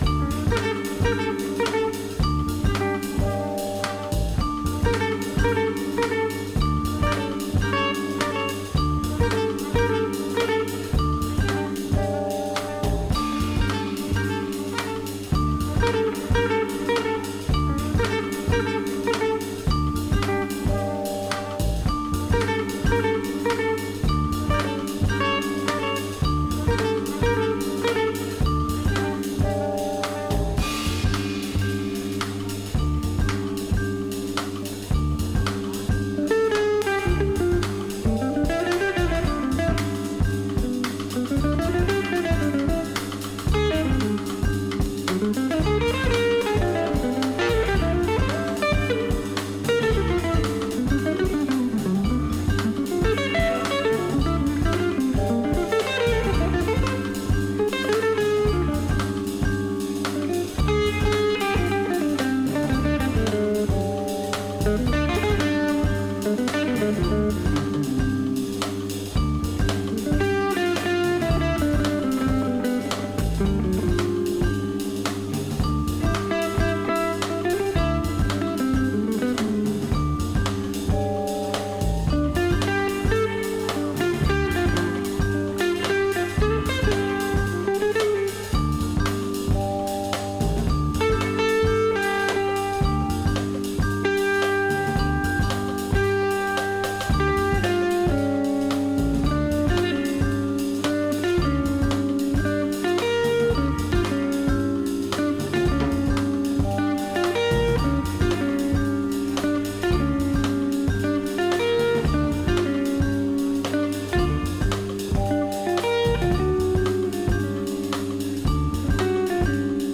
drummer/producer
Hip hop
Jazz, Jazzy hip-hop